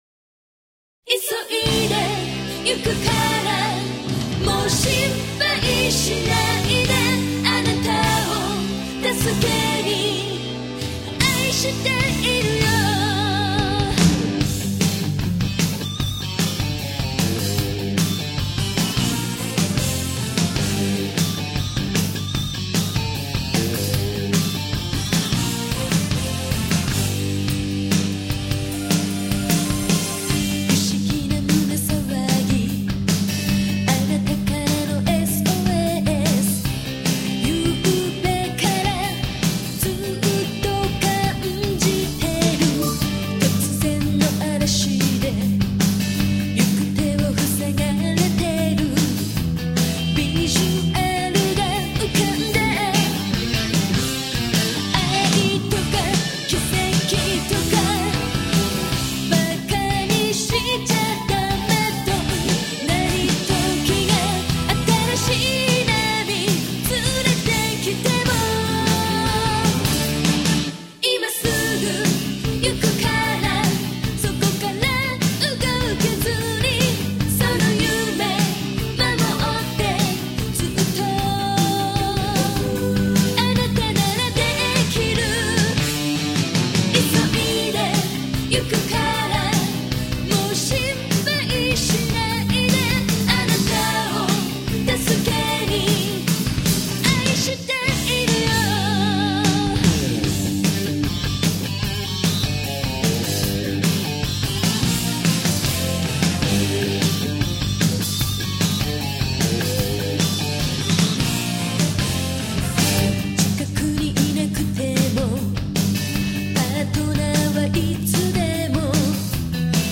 (오케스트라 사운드가 거의 사용되지 않았으며 기계적인 사운드에만 의존.)
밝은 분위기군요.